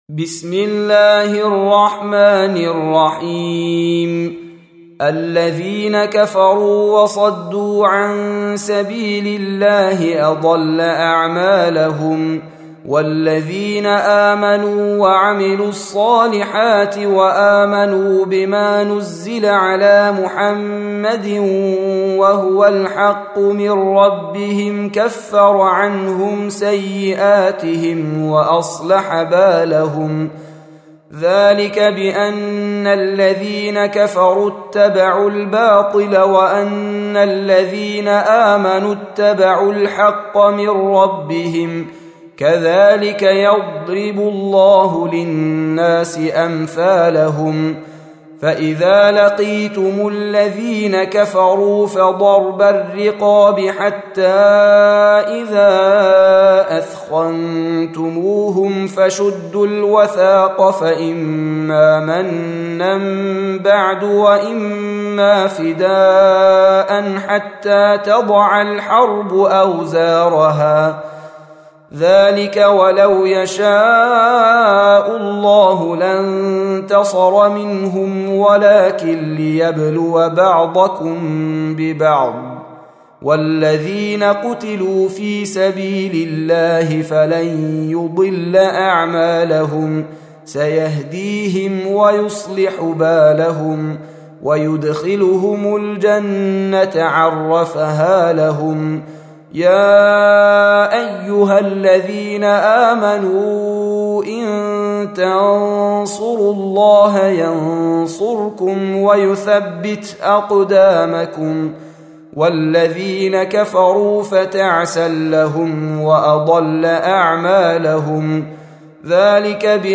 47. Surah Muhammad or Al-Qit�l سورة محمد Audio Quran Tarteel Recitation
Surah Repeating تكرار السورة Download Surah حمّل السورة Reciting Murattalah Audio for 47.